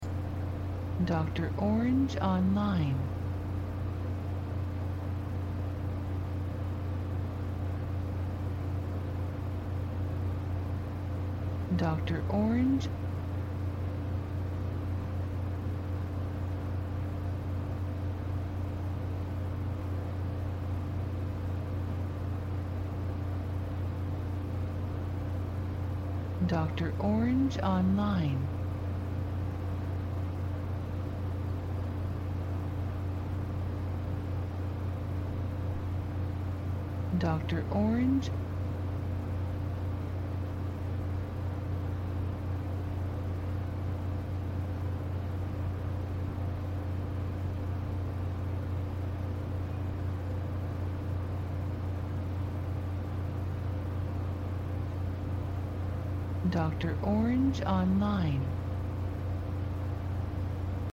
Ambiente Mono Interior Aire Acondicionado
INTERIOR AMBIENTE GENERICO, AIRE ACONDICIONADO, SONIDO PERMANENTE.
Archivo de audio MONO, 96Khz – 24 Bits, WAV.
GRATIS-AMBIENTE-HABITACION-AC-ENCENDIDO-002-96KHZ.mp3